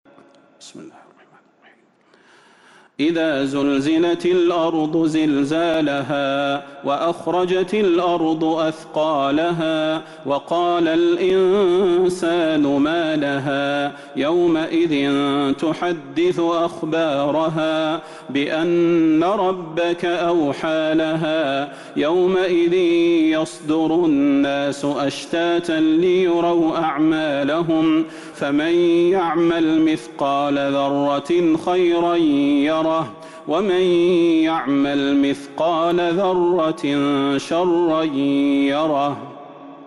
سورة الزلزلة Surat Az-Zalzalah من تراويح المسجد النبوي 1442هـ > مصحف تراويح الحرم النبوي عام 1442هـ > المصحف - تلاوات الحرمين